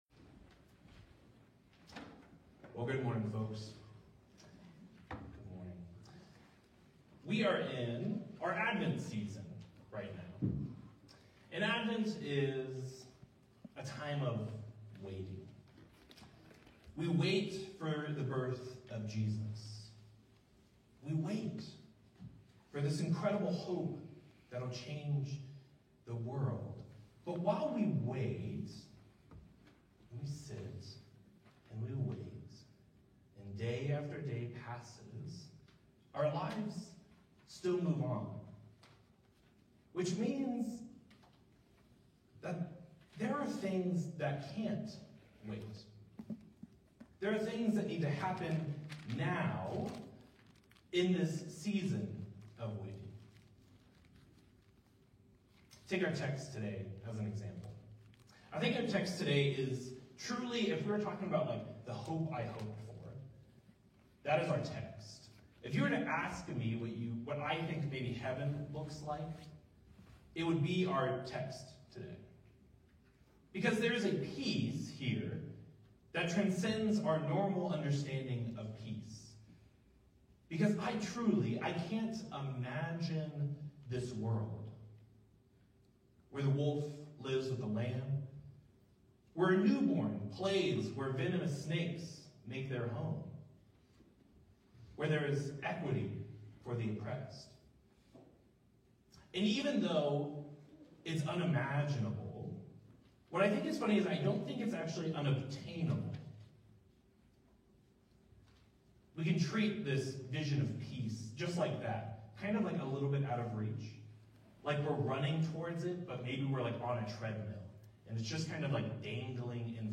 Sermons | Covenant Presbyterian DTC